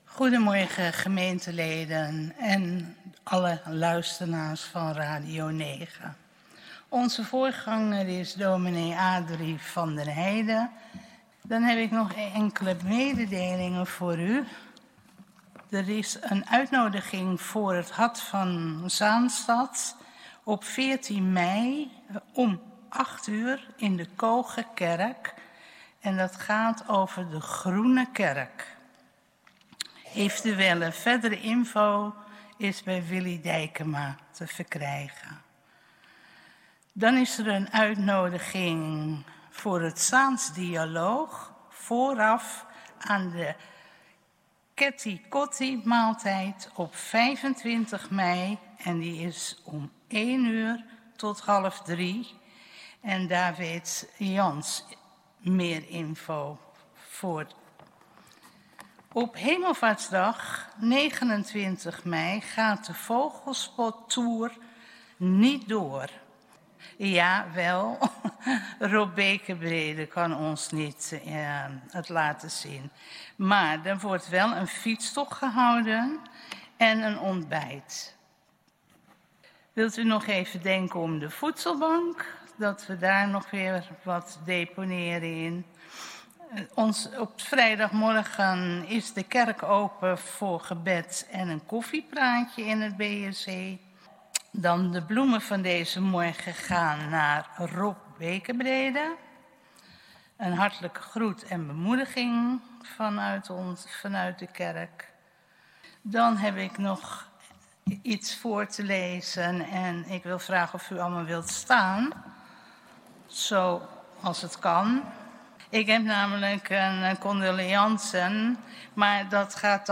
Protestantse Gemeente Oostzaan - Zondag 10.00 uur Kerkdienst in de Grote Kerk!
Kerkdienst geluidsopname